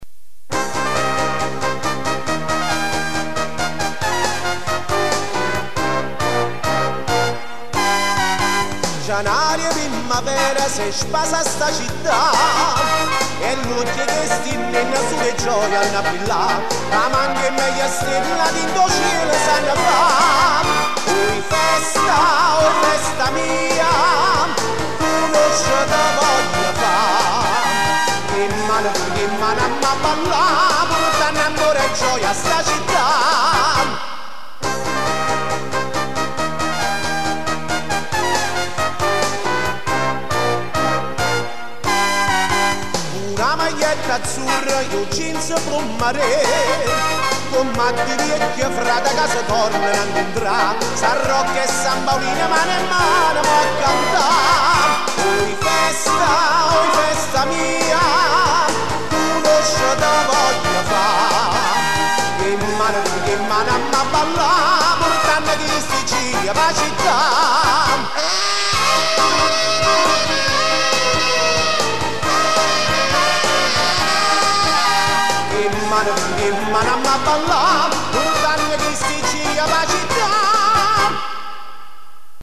Flumeri 1995
Divisione Musicale: dei F.lli Acierno